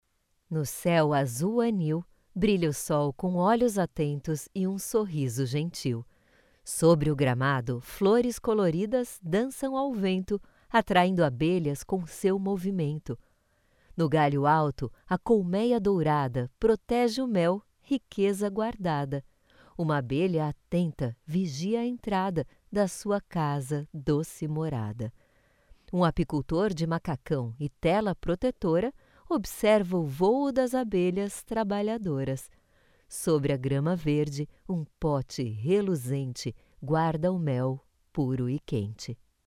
Trecho_-AD_O-Alfabeto-dos-Animais-1.mp3